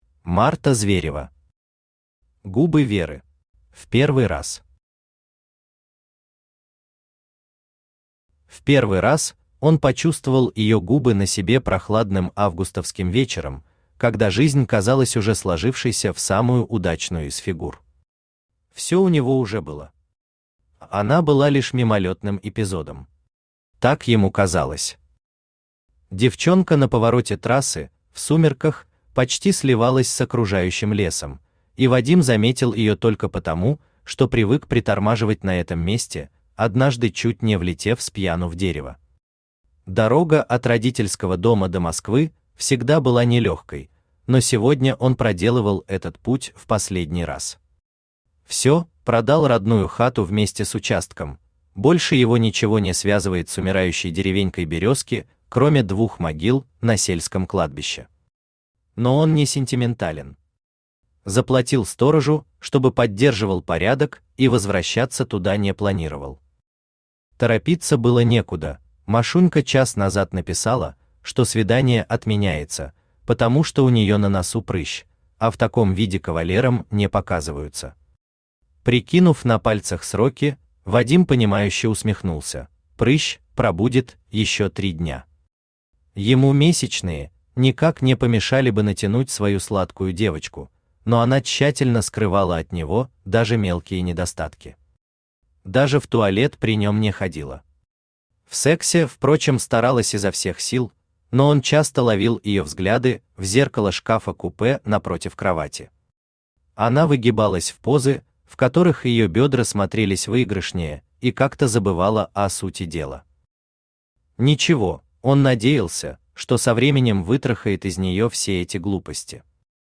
Aудиокнига Губы Веры Автор Марта Зверева Читает аудиокнигу Авточтец ЛитРес.